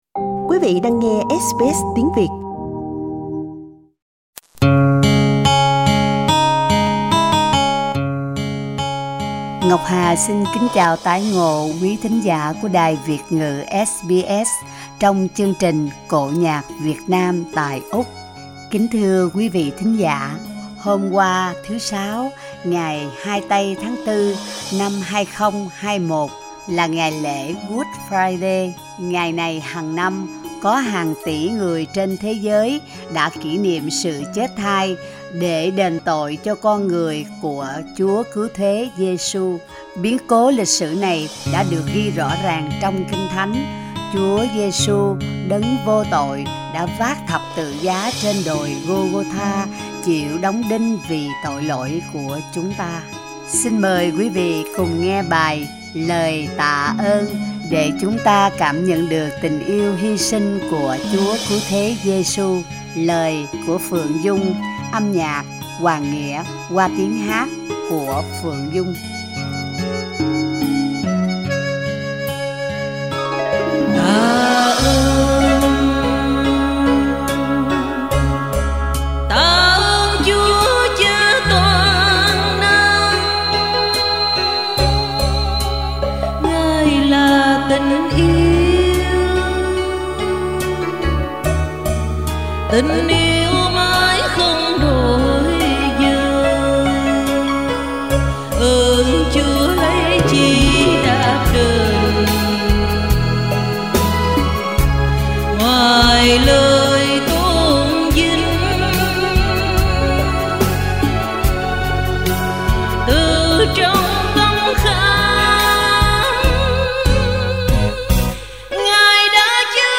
Cổ nhạc Việt Nam tại Úc: Lễ Phục Sinh